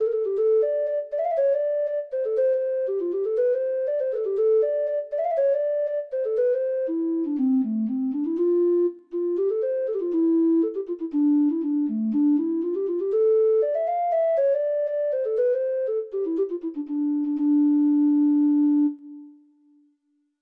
Treble Clef Instrument version